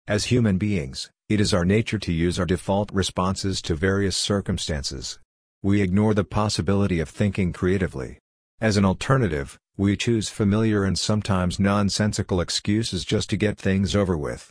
Audio Articles – Male and Female Voices
Male Recording Sample
Excuses-Male-Audio-Sample.mp3